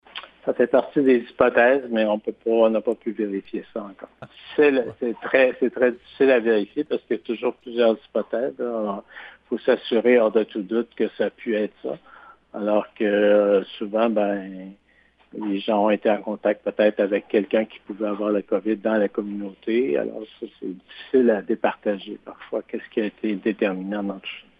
Le directeur de la Santé publique, le Dr Yv Bonnier-Viger, affirme qu’il ne s’agit pas d’une éclosion de l’envergure de celle qui a touché le Centre de détention de New Carlisle;